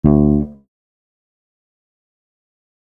D FALL OFF.wav